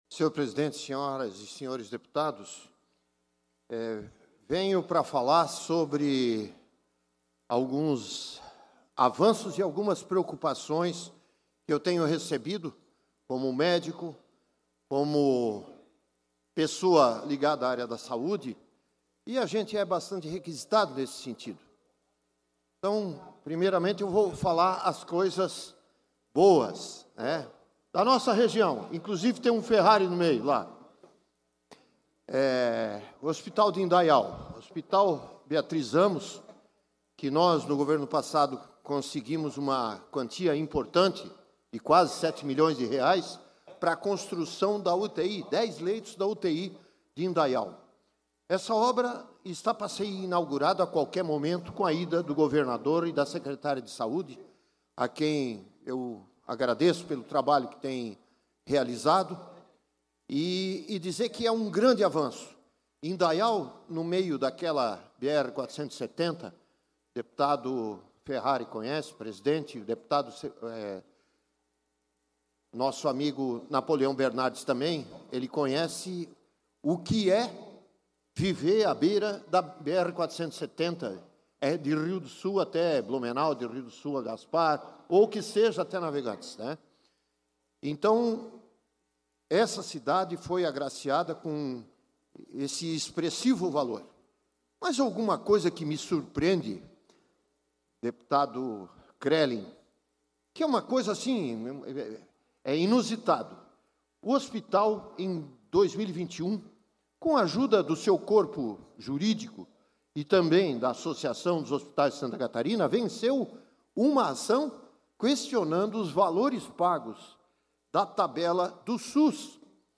Pronunciamento da sessão ordinária desta terça-feira (24)